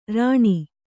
♪ rāṇi